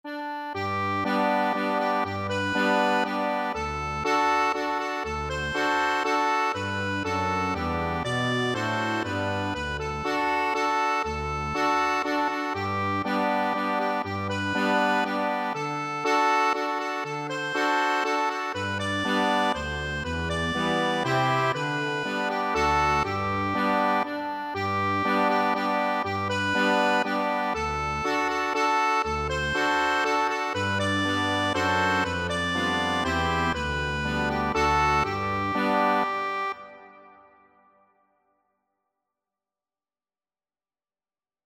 3/4 (View more 3/4 Music)
Lively ( = c.120)
Accordion  (View more Easy Accordion Music)